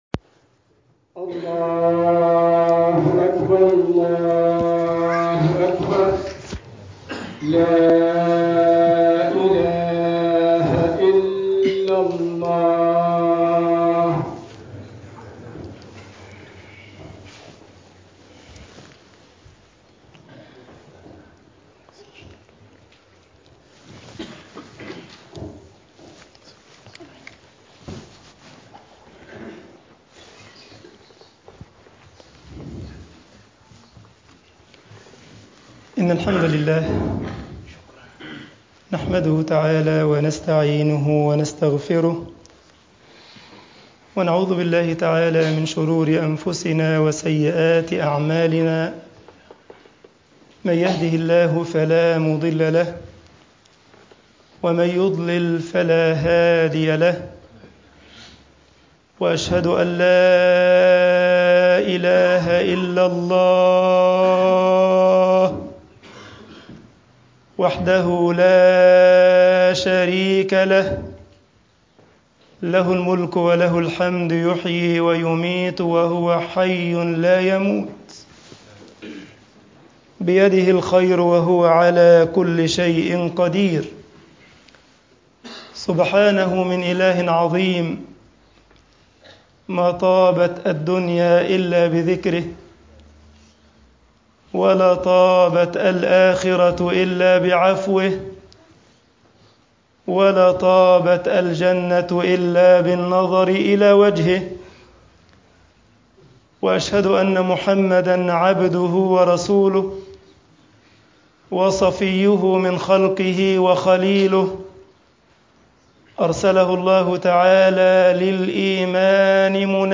Freitagsgebet_al esmat min fetnat al-ilm4.mp3